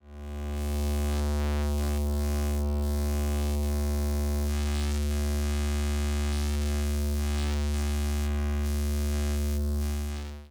Crystal Buzz.wav